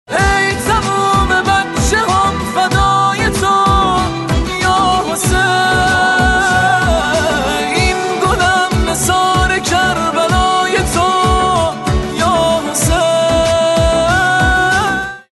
زنگ موبایل
رینگتون پرانرژی و باکلام